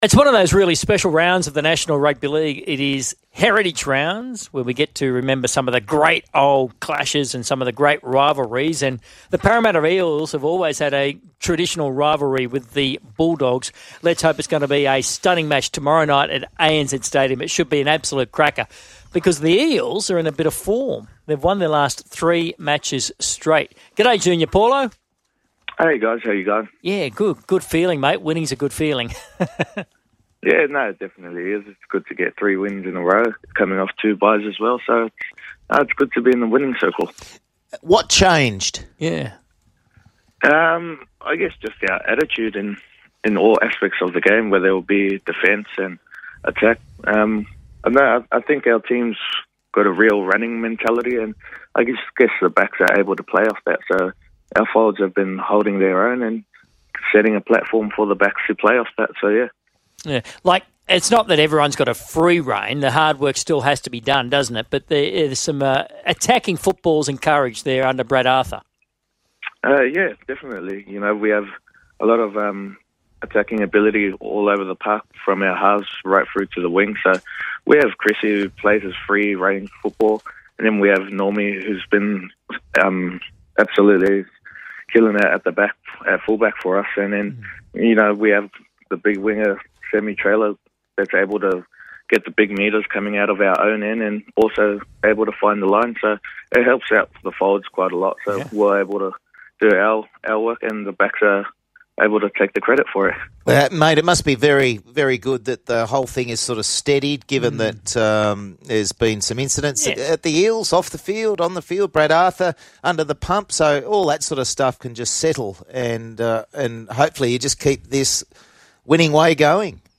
Talks about a fair few things and speaks very well for a young player. Good interview.